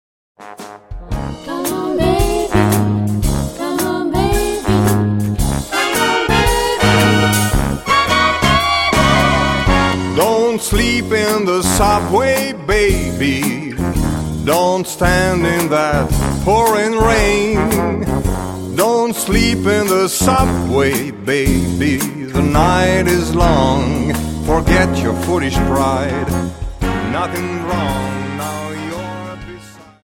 Dance: Slowfox 28